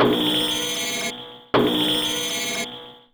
yellowAlert.wav